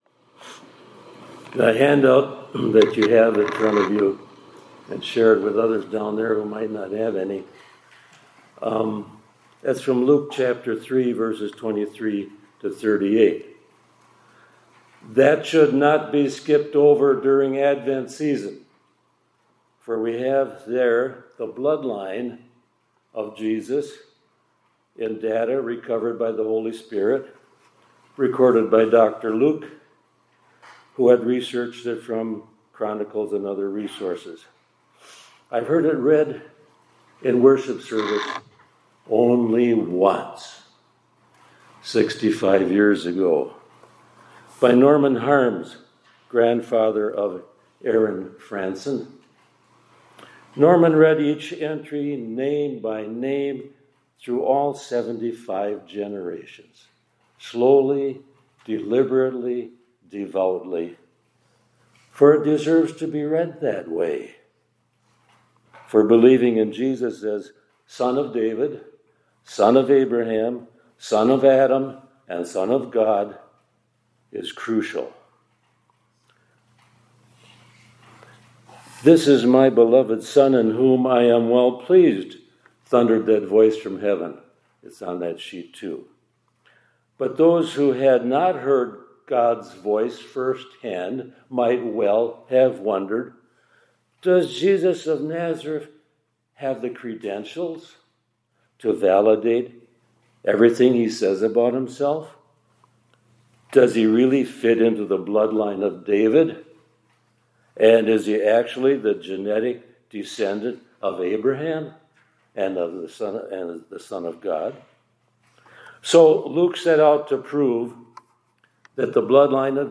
2025-12-16 ILC Chapel — God Keeps His Promises